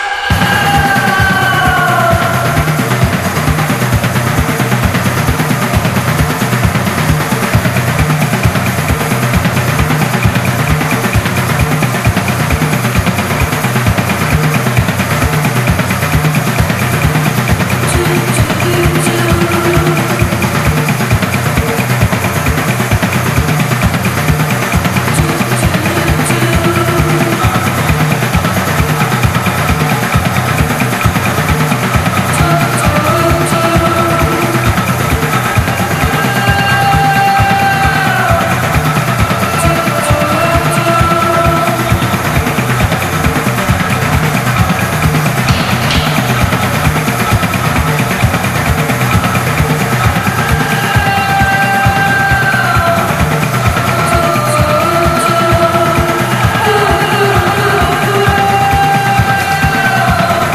NEW WAVE / POST PUNK
NEW WAVE / POST PUNK重要バンドだらけの、入門編にも最適なライヴ・オムニバス！